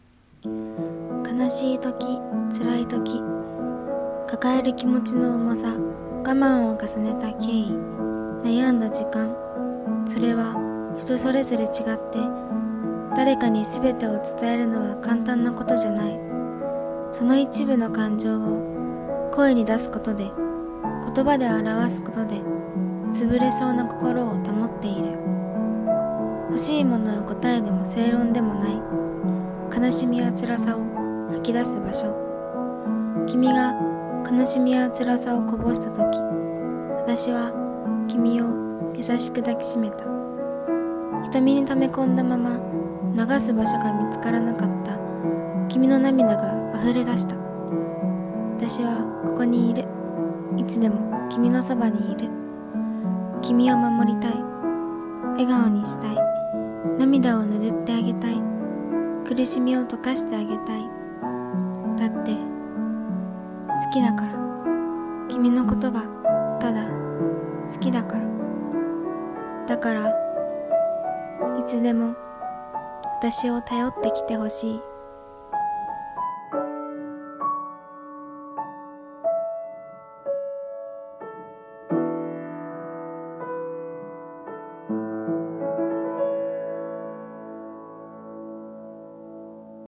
声劇【心】